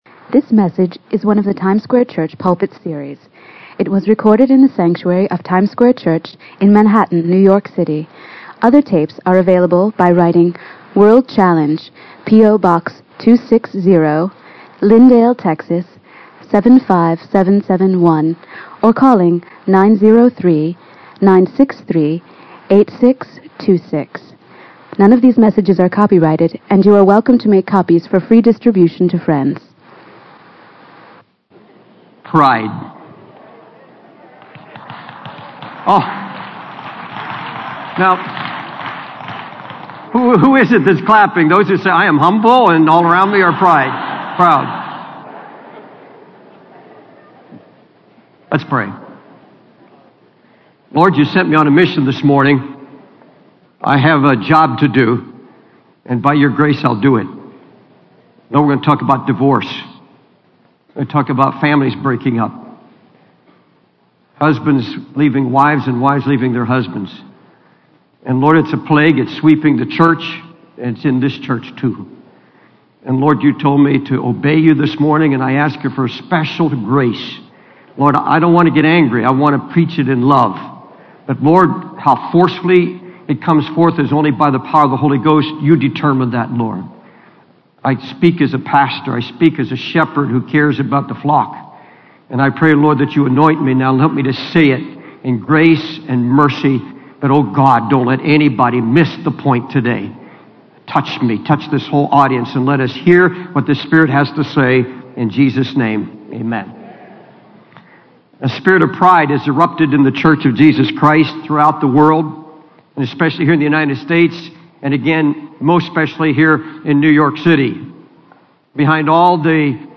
In this sermon, the preacher addresses the issue of divorce and the breaking up of families, stating that it is a plague that is sweeping the church. The preacher emphasizes the importance of humility and obedience to God in order to overcome this issue. The sermon includes a moment of prayer and reflection for couples to seek God's guidance and repentance if necessary.